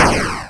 explode_a.wav